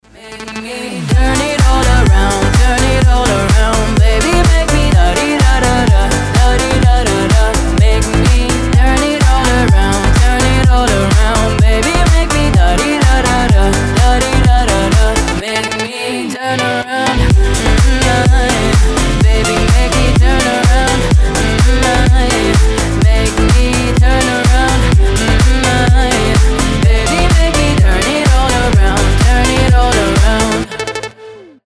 Dance Pop
house